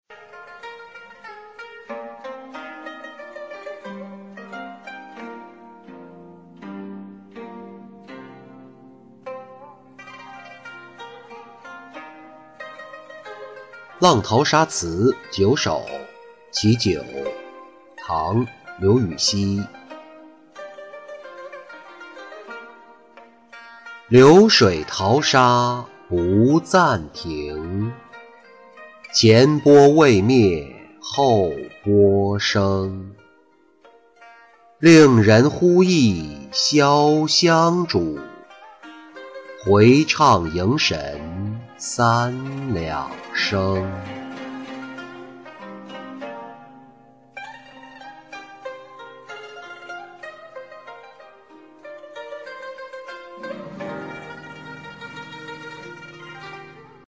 浪淘沙·其九-音频朗读